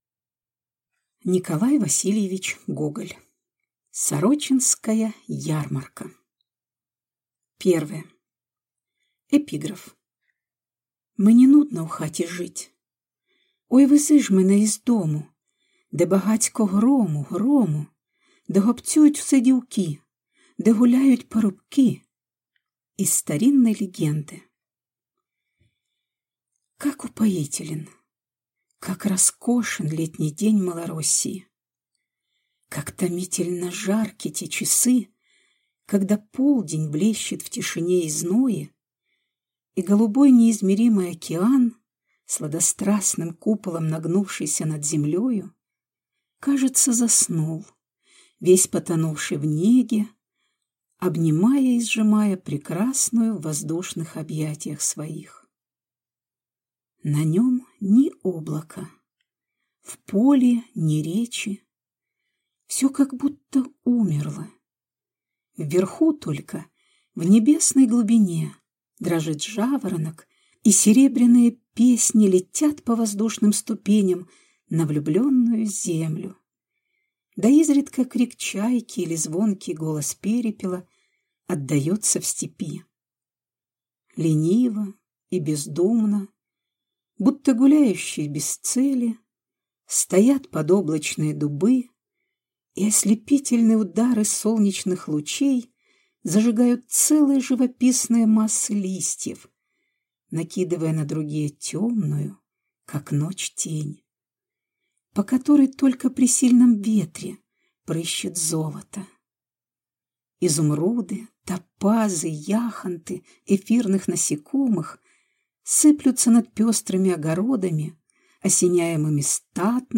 Аудиокнига Сорочинская ярмарка | Библиотека аудиокниг